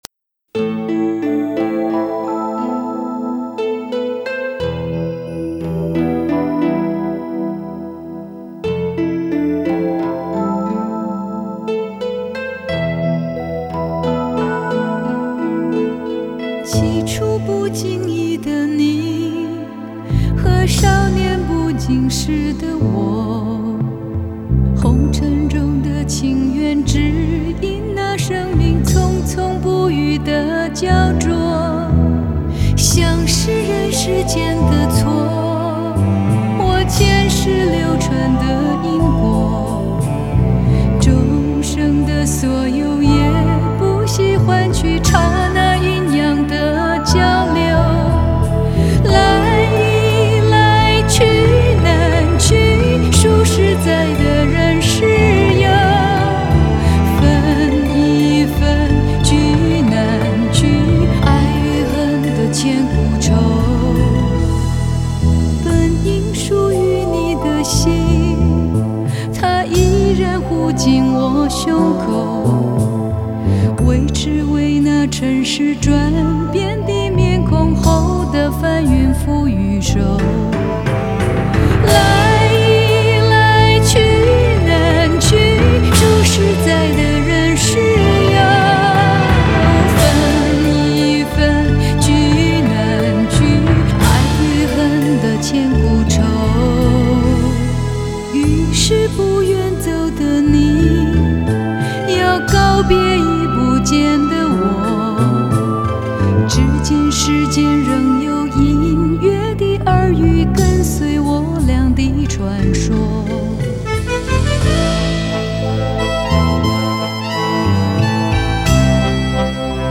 类别: 流行